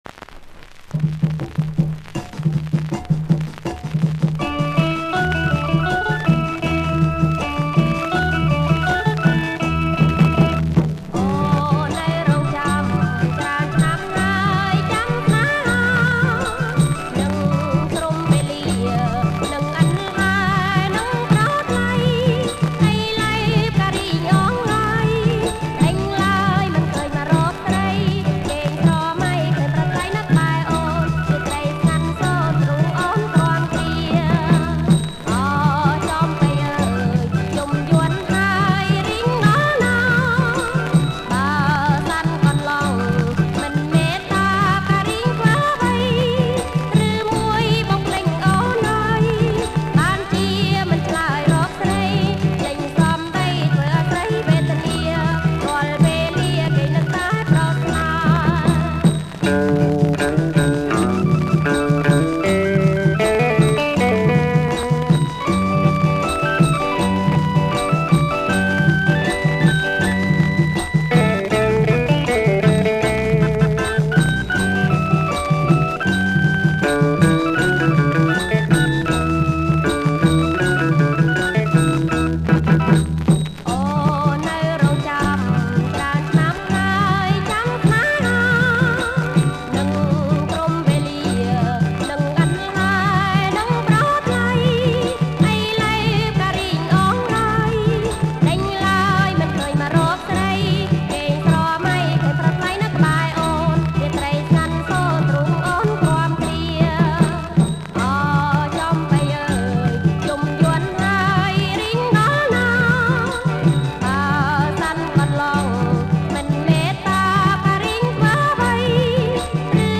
• ប្រគំជាចង្វាក់ រាំវង់
ប្រគំជាចង្វាក់ រាំវង់